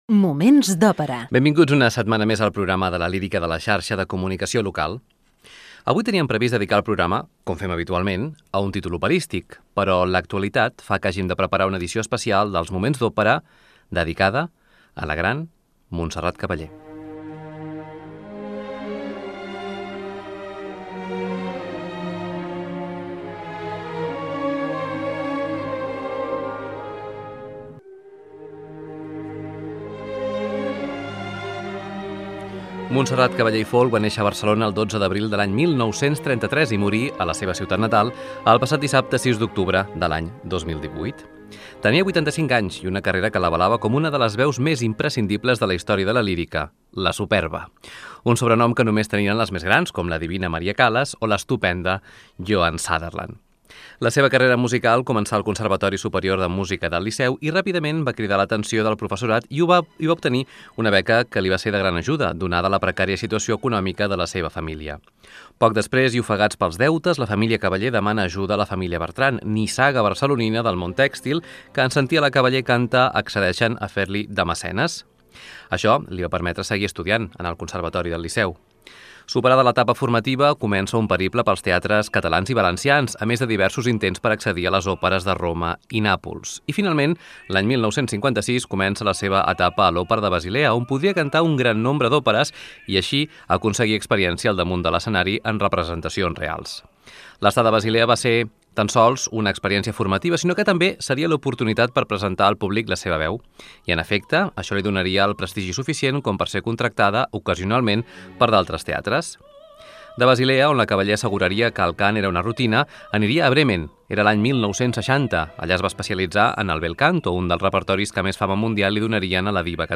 Indicatiu del programa i fragment de l'especial emès amb motiu de la mort de la soprano Montserrat Caballé.
Musical